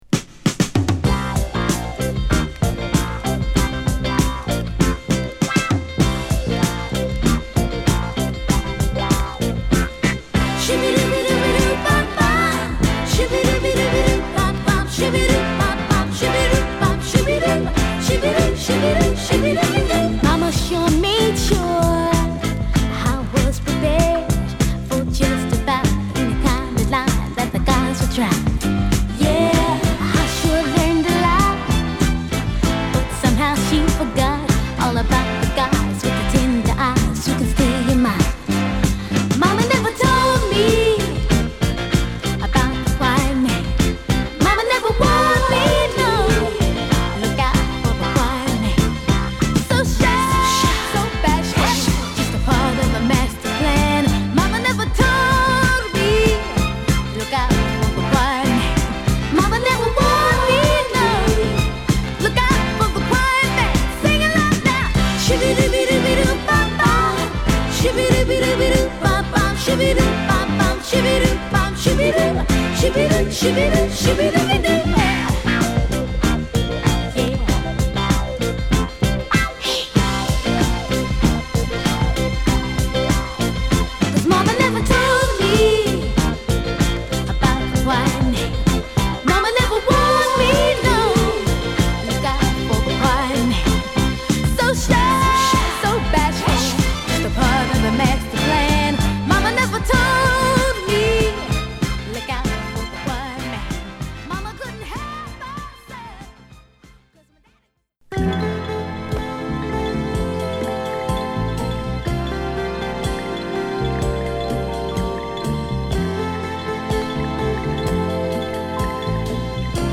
瑞々しいメロディ／ヴォーカルを聴かせるヤングソウルA